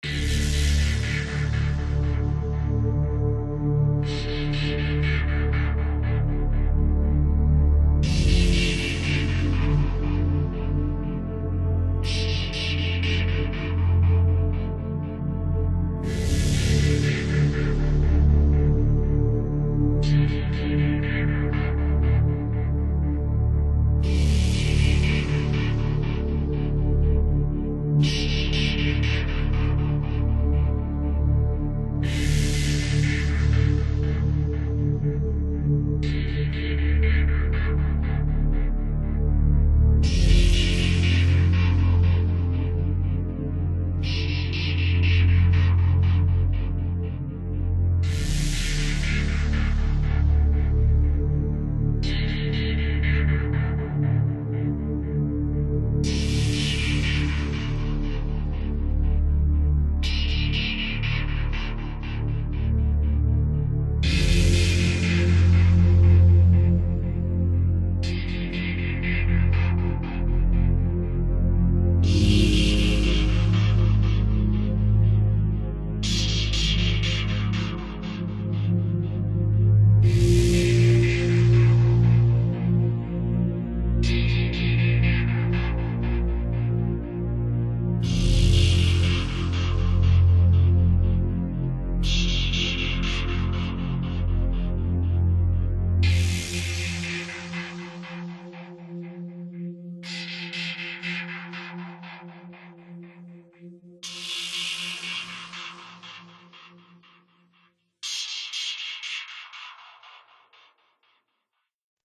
They felt like space - big and indifferent.